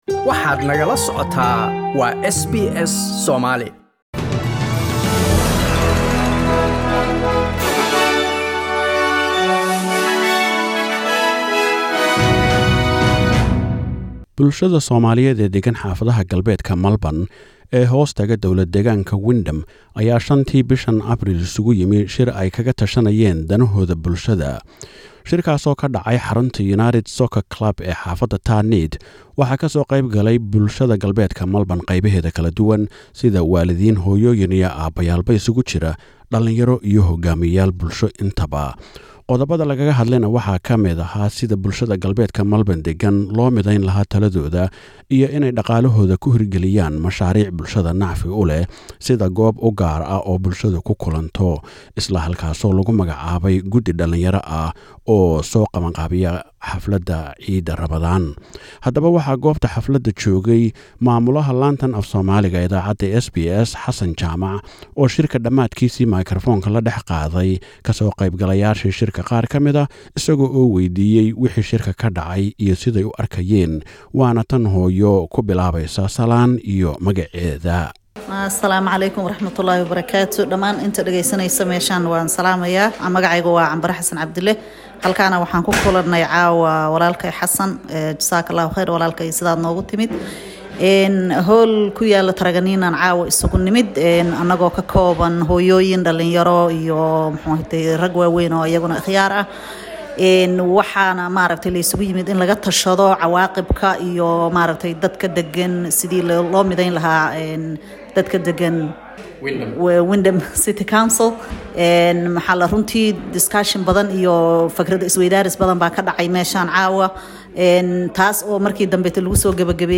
Shir si heer sare ah loo soo agaasimay oo ay isugu yimaaddeen jaalliyadda Soomaaliyeed ee deggan xaafahadaha galbeedka Melbourne, sida Tarneit, werribee, Hoppers Crossing ee dowlad-deegaanka Wyndham ayaa ka dhacay xarunta United Soccer Club ee Tarneit. Shirkaa waxay bulshadu kaga tashadeen xoojinta xidhiidhka iyo wax-wada-qabsiga bulshada, midaynta taladooda iyo arrimo kale.